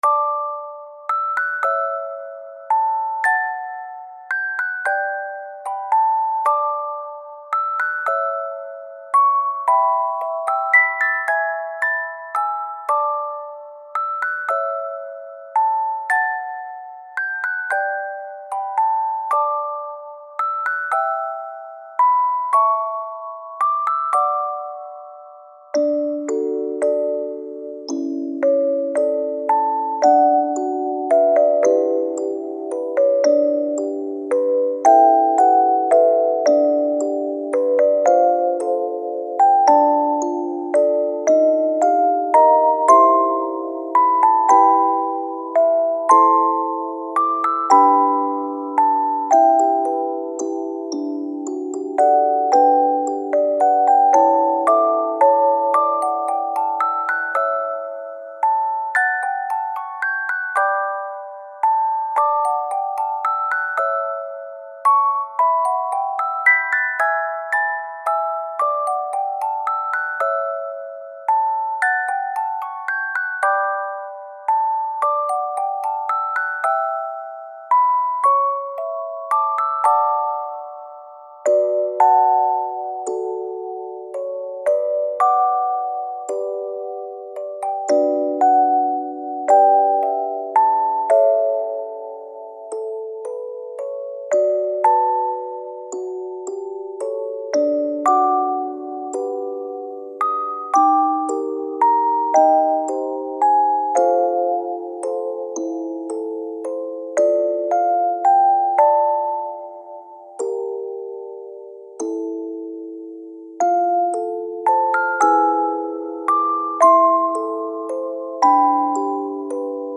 睡眠用の、静かなオルゴールBGMです。